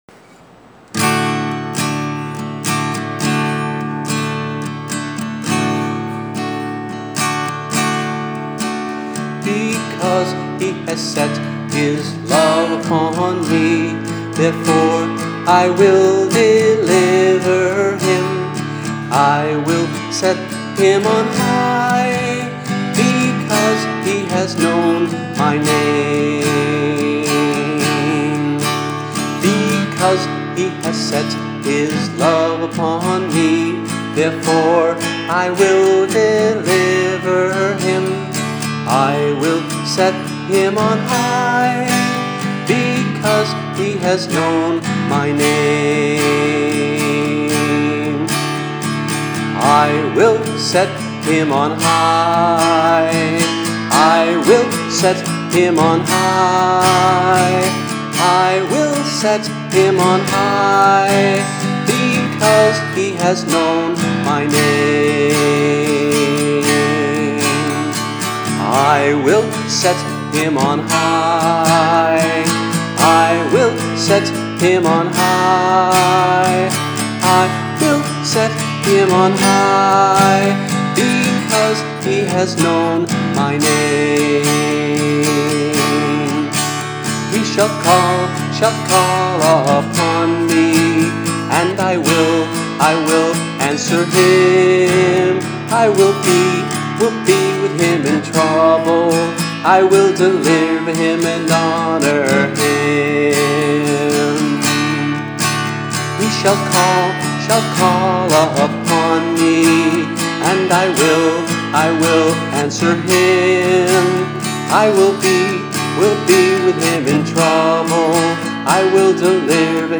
voice and guitar